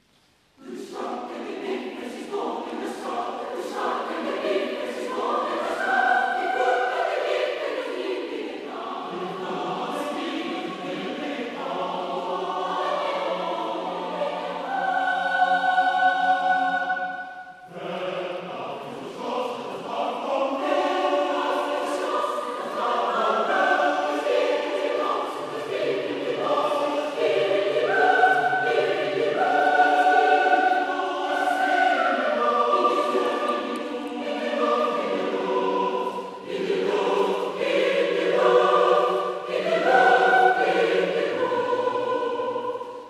横浜オラトリオ協会合唱団 - 試聴室
こちらでは、最近の演奏会の録音から、その一部分を１分ほどお聞きいただけます。
第７５回定期演奏会(2011年) メンデルスゾーン作曲「野に歌う６つの歌」より